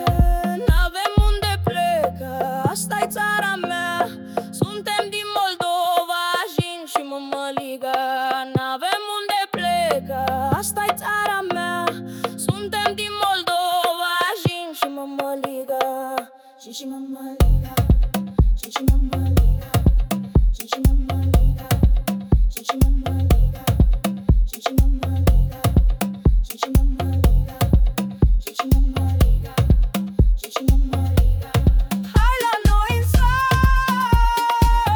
Afro-Beat African Afro-Pop
Жанр: Поп музыка